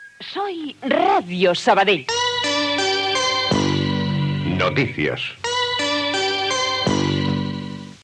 Identificació de la ràdio i del servei de notícies